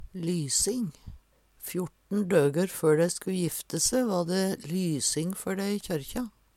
lysing - Numedalsmål (en-US)